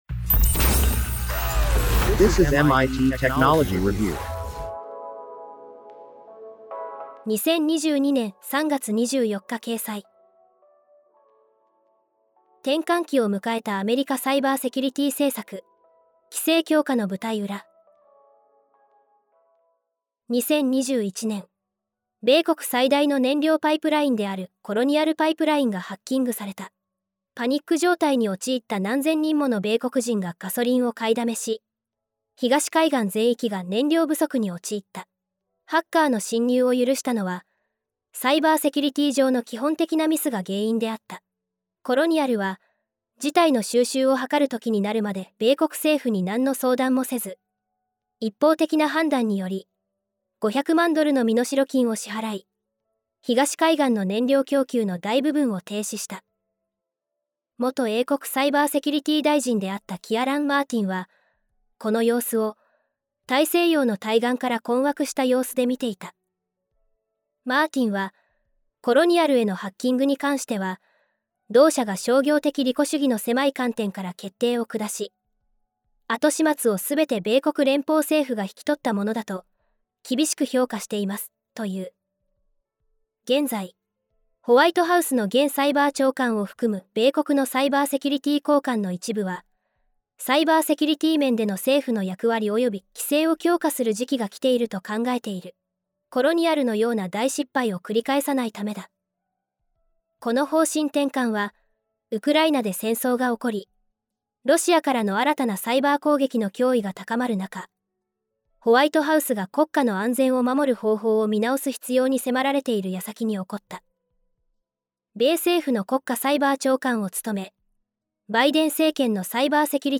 なお、本コンテンツは音声合成技術で作成しているため、一部お聞き苦しい点があります。